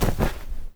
glider_close.wav